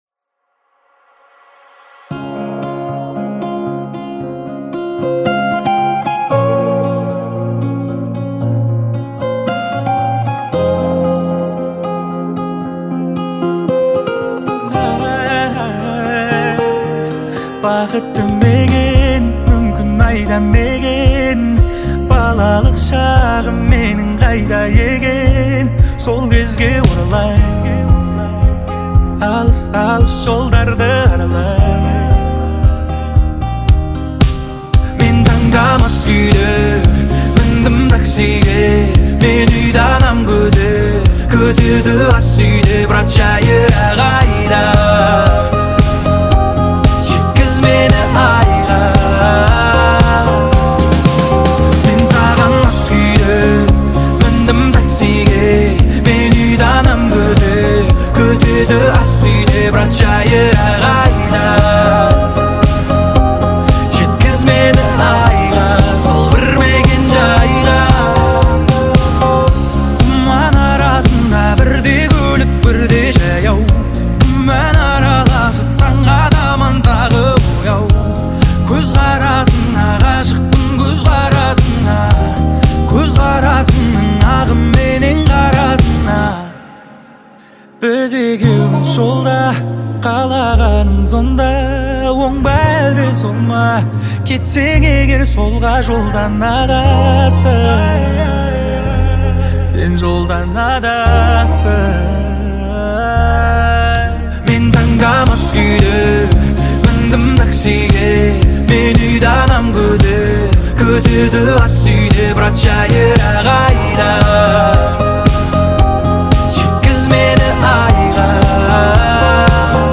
• Категория: Казахская музыка